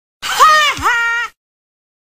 Haha Sound Button - Free Download & Play